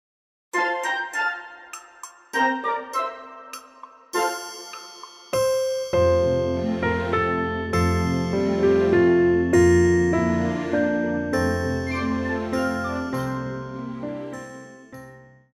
古典
钢琴
乐团
圣诞歌曲,圣歌,教会音乐,古典音乐
钢琴曲,演奏曲
独奏与伴奏
有主奏
有节拍器